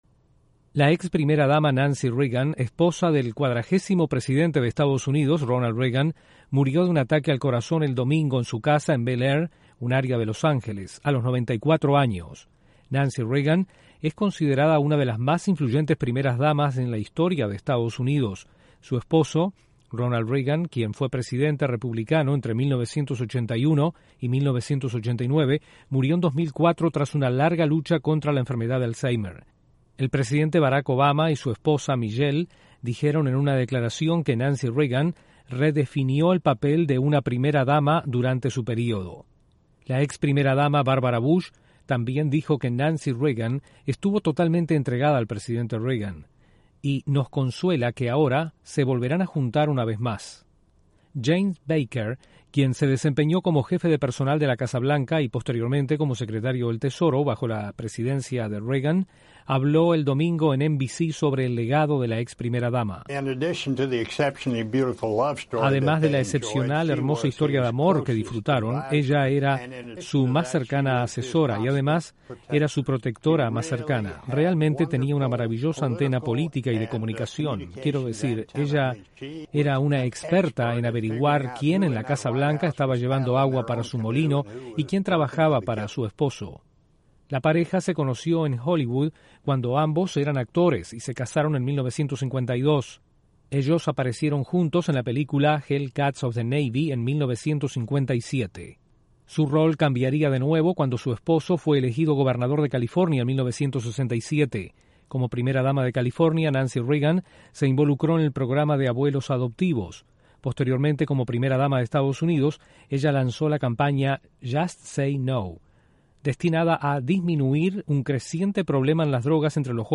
Muró a los 94 años la exprimera dama de Estados Unidos Nancy Reagan. Desde la Voz de América en Washington informa